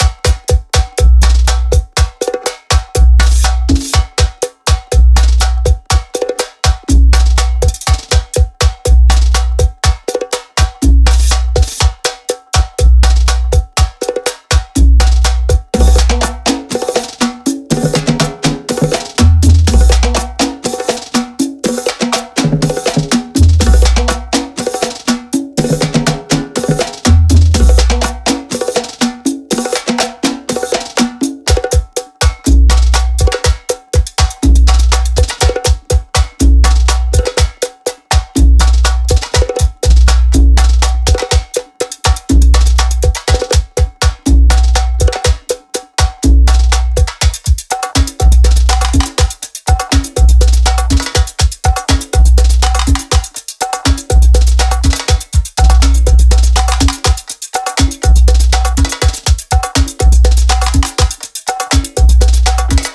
Percussão Pagodão Baiano
Loops e one-shots autênticos da Swingueira, com ritmos e viradas icônicas que capturam a essência do Pagode Baiano.
Inclui Loops Gerais, Malacaxeta, Repique, Surdo e Timbal – todos gravados em alta qualidade.
PAGODE-BAIANO-MASTER-1.wav